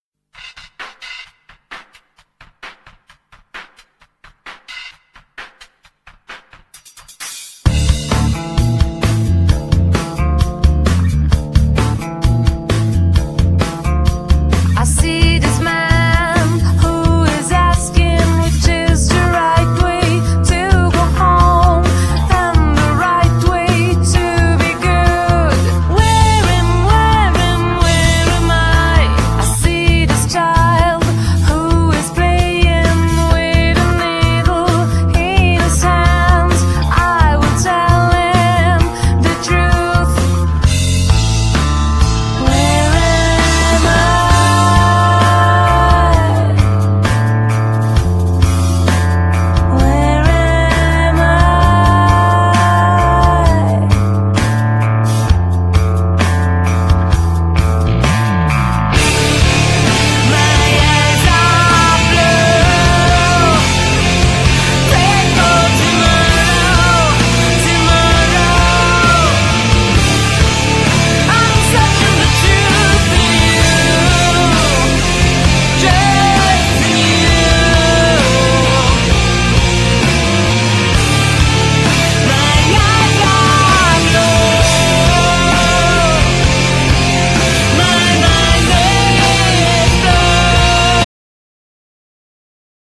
Genere : Pop rock
"Hard-Candy": dolce-duro.